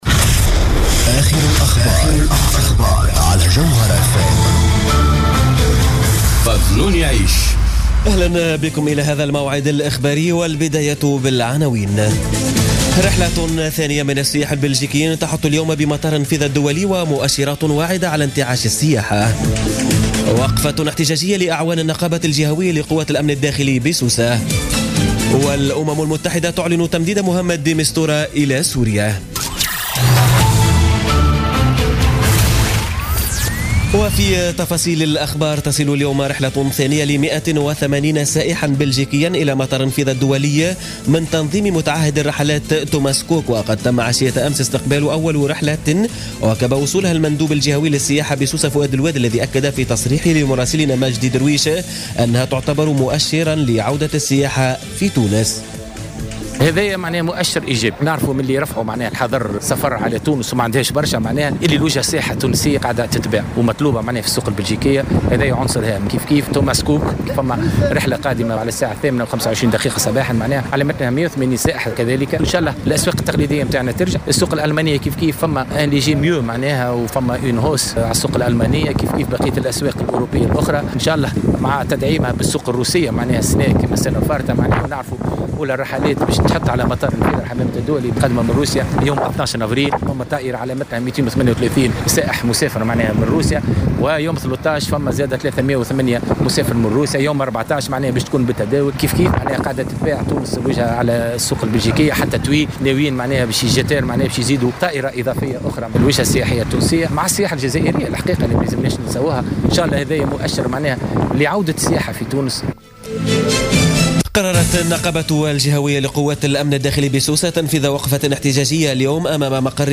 نشرة أخبار منتصف الليل ليوم السبت غرة أفريل 2017